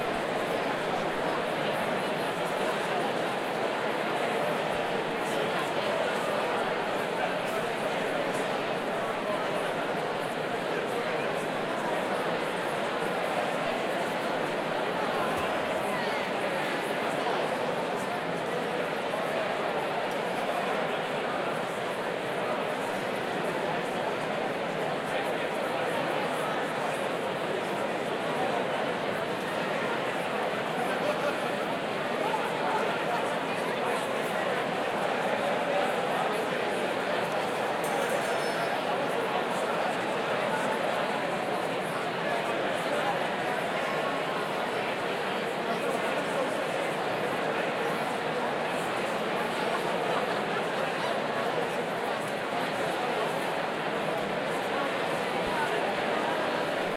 teenageCrowdFarLoop.ogg